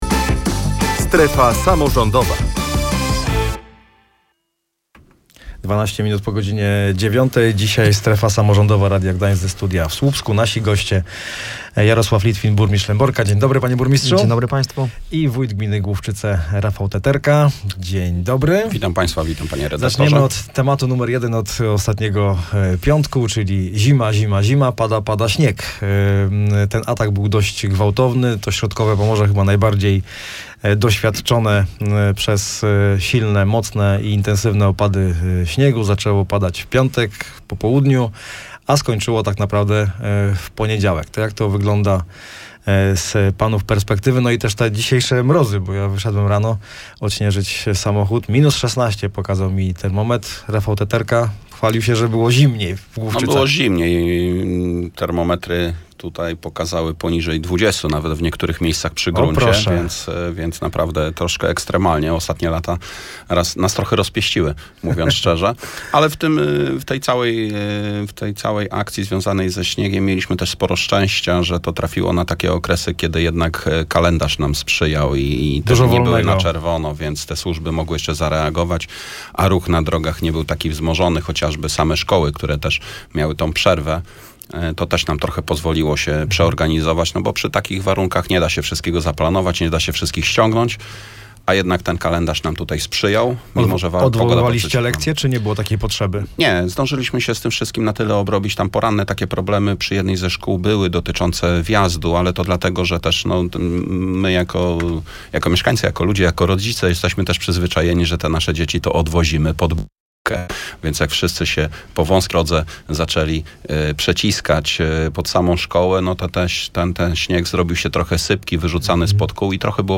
Ubiegłotygodniowy atak zimy odciął od świata kilka miejscowości w powiecie słupskim – przyznają samorządowcy. O tym, jak półmetrowy opad śniegu wpłynął na funkcjonowanie gmin, opowiadali goście „Strefy Samorządowej” w Radiu Gdańsk: Rafał Teterka, wójt gminy Główczyce, oraz Jarosław Litwin, burmistrz